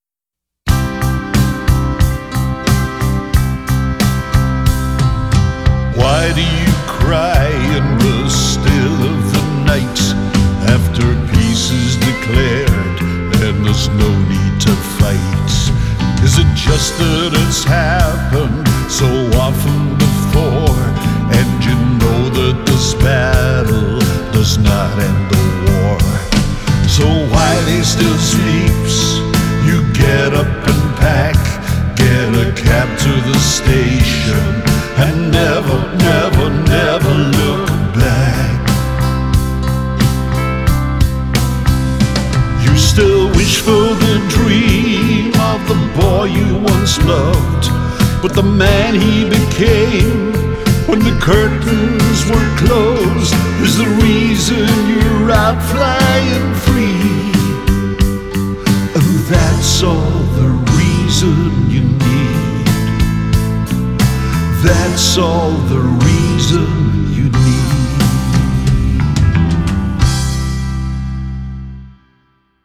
So, I’ll keep the F for the 3rd line, and go down the minor route for line 1.
• I’ll put in a Dm, then I’ll change it to D major – one bar minor , then one bar major can be very effective in a song
A hookline can always stand a bit of repetition, so I’ll do that twice, but with a little bit of the same kind of variation we used on the verse: